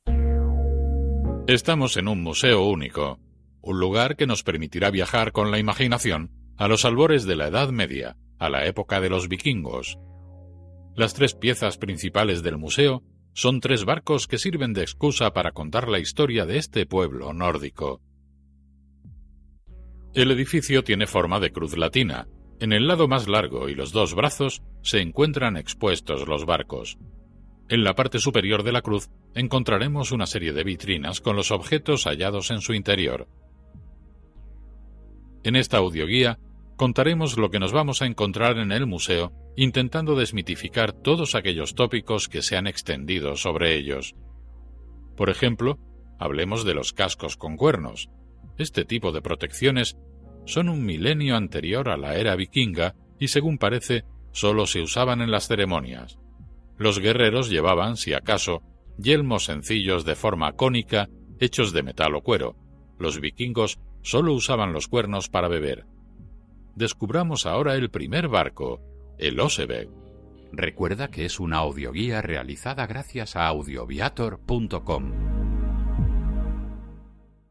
audioguía_Museo_de_los_Barcos_Vikingos_Oslo_ES_01.mp3